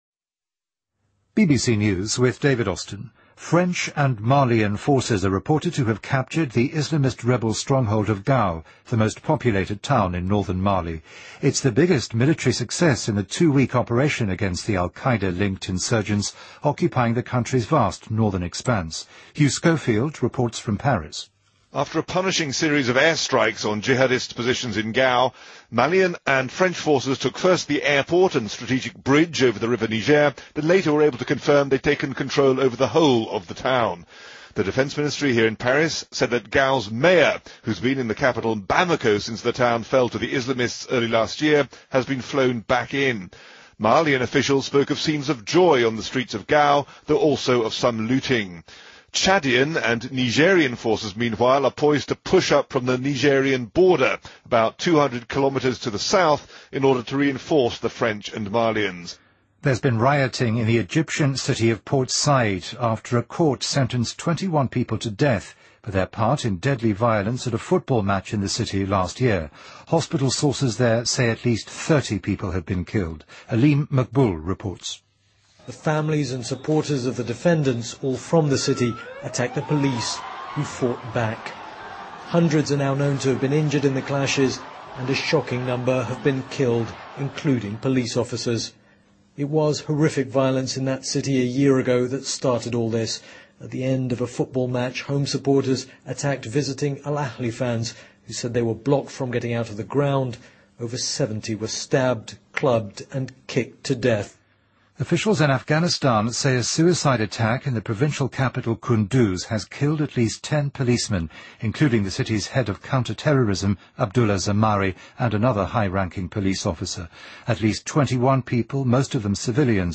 BBC news,黑客组织Anonymous入侵美国量刑委员会的网站